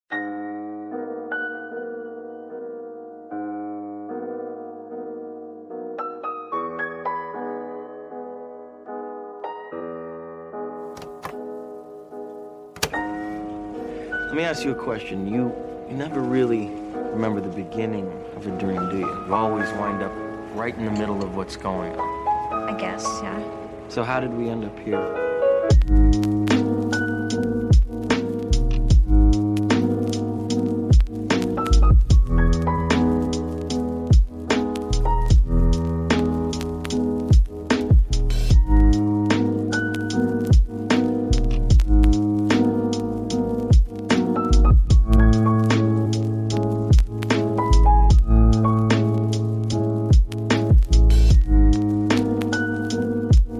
Aucun bruit parasite, aucune coupure soudaine.